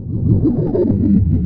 weirdsound3.wav